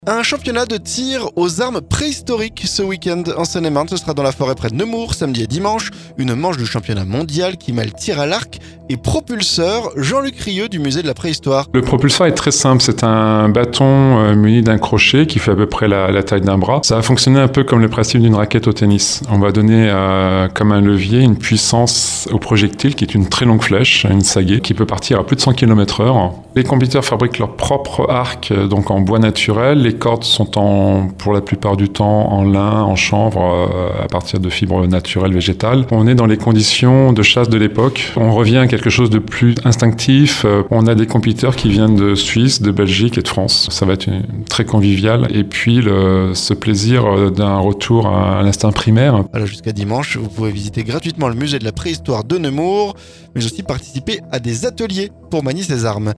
*Reportage